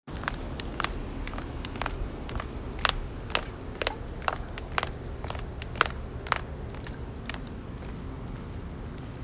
描述：卡通爆裂声。
Tag: 泡沫 卡通 脸颊流行 软木 啪式 弹出